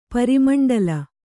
♪ pari maṇḍala